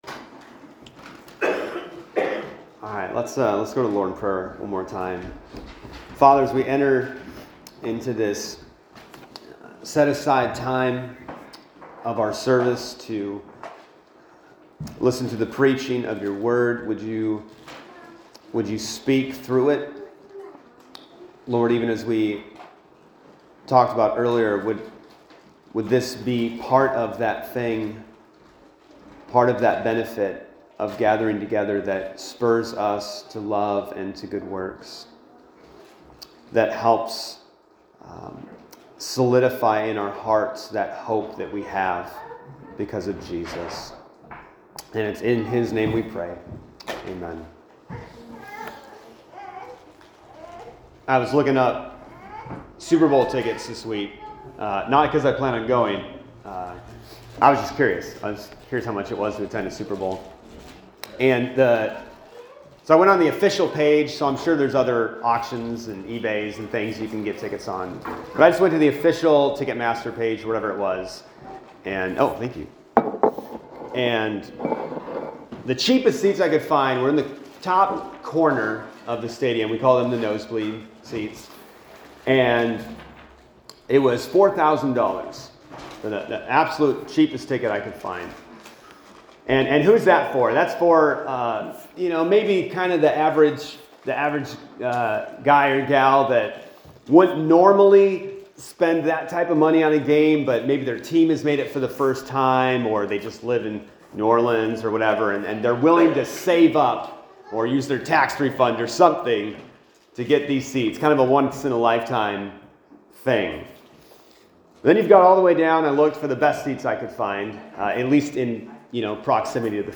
Sermons | Community Church of Hokah